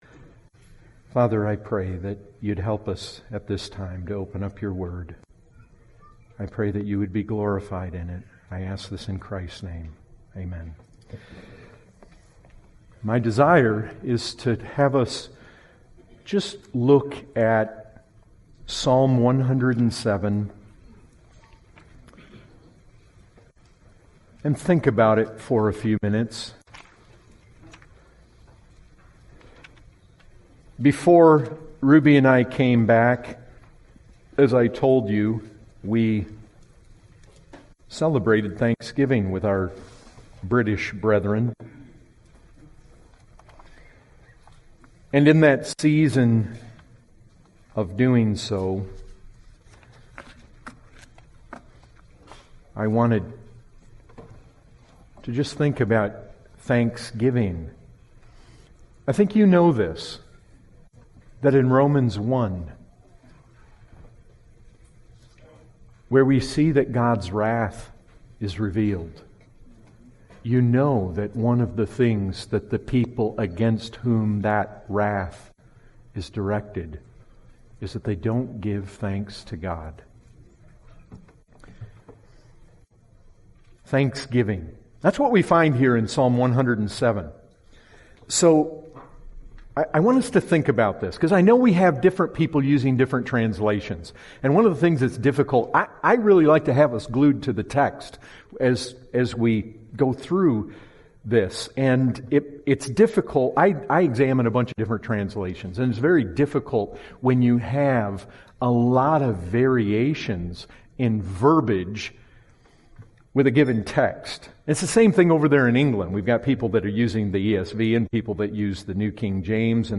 Category: Full Sermons